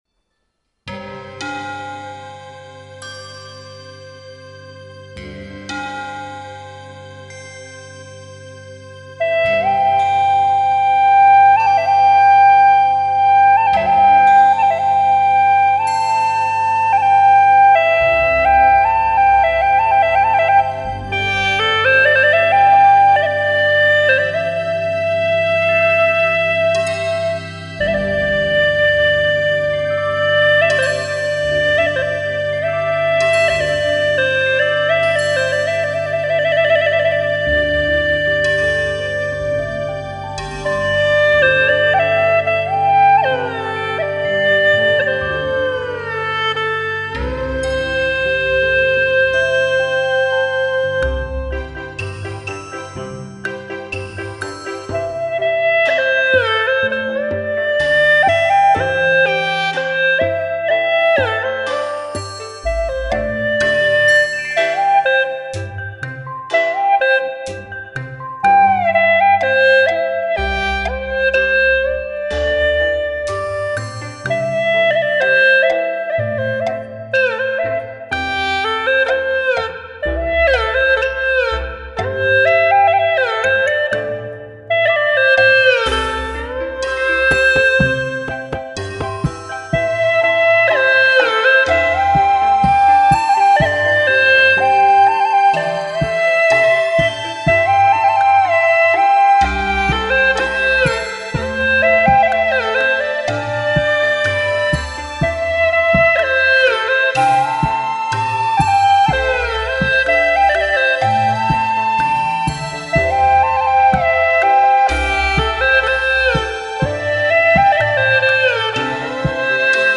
调式 : C 曲类 : 独奏